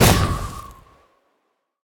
Minecraft Version Minecraft Version snapshot Latest Release | Latest Snapshot snapshot / assets / minecraft / sounds / mob / breeze / wind_burst1.ogg Compare With Compare With Latest Release | Latest Snapshot
wind_burst1.ogg